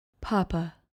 Pronounced: pah-pah